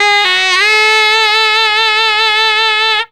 COOL SAX 12.wav